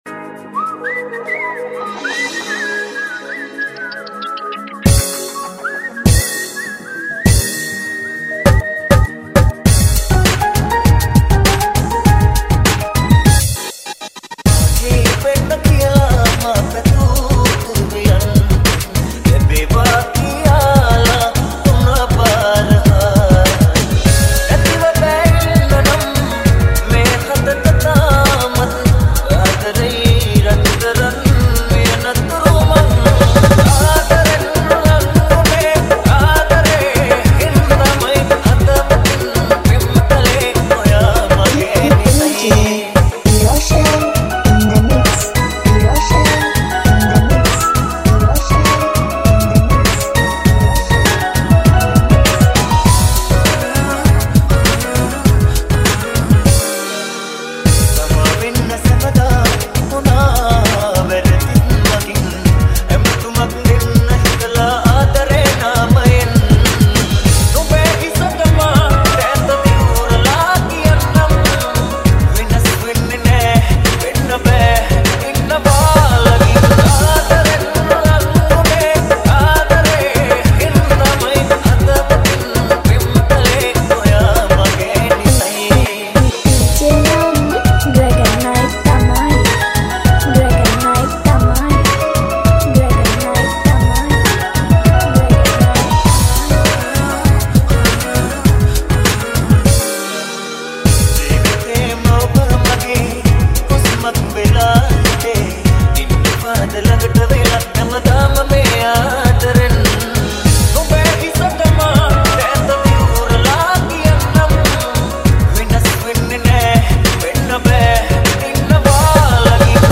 Hip hop style Dj Remix